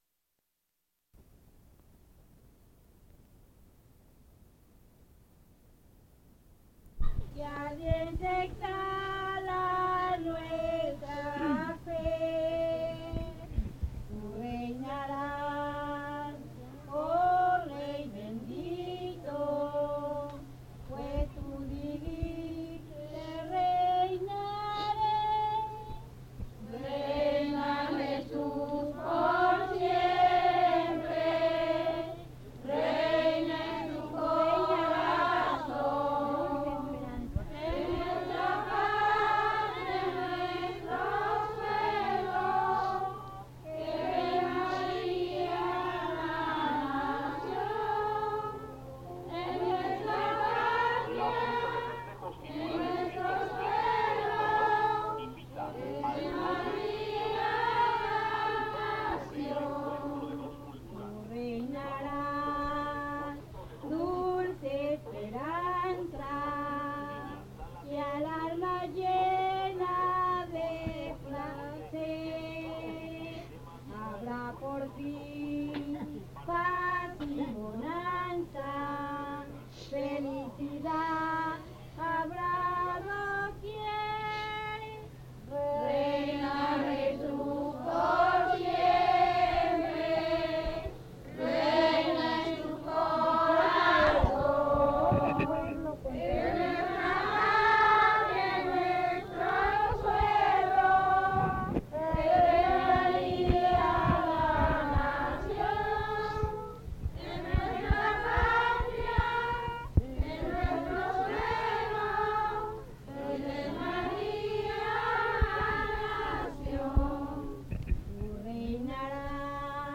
Fiesta del Señor Santiago